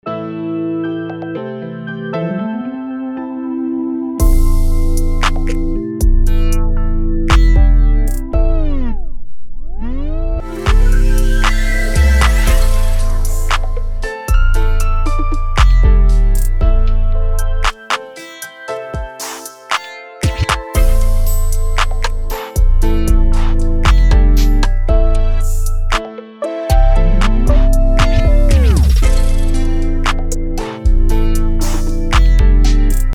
BPM: 116
Key: A minor